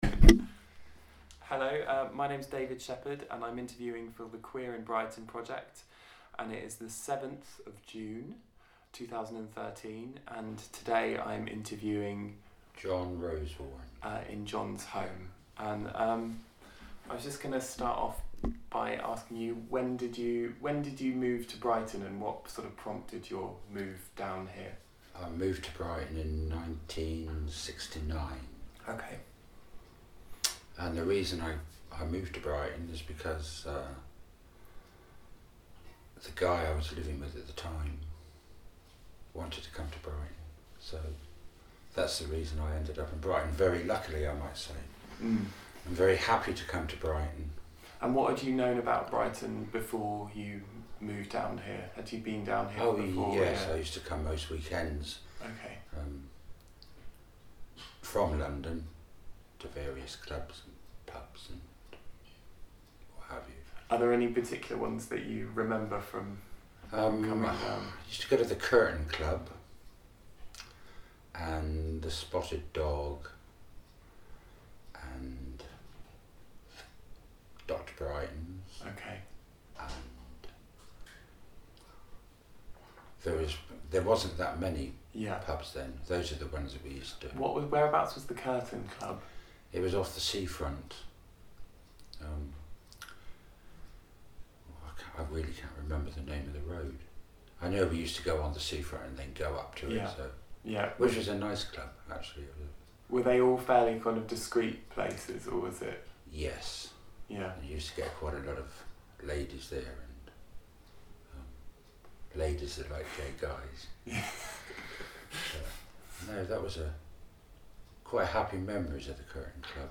Oral history interview collected as part of the Queer in Brighton project 2013-2014.